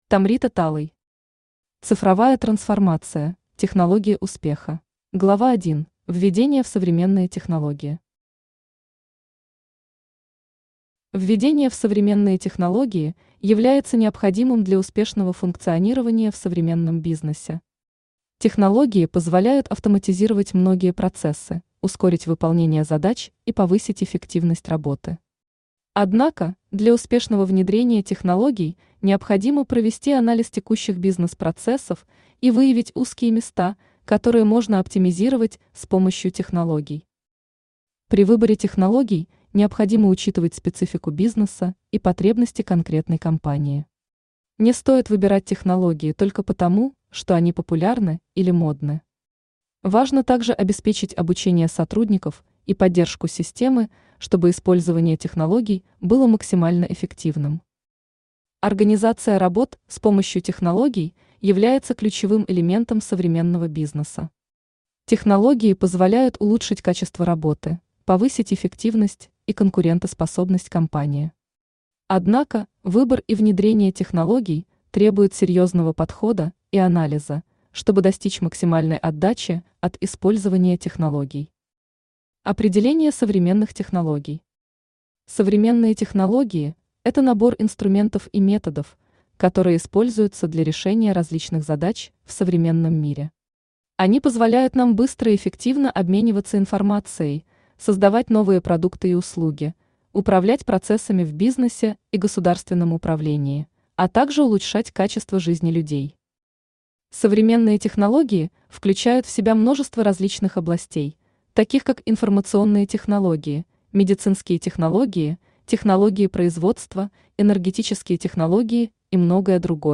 Aудиокнига Цифровая трансформация: Технология успеха Автор Tomrita Talay Читает аудиокнигу Авточтец ЛитРес.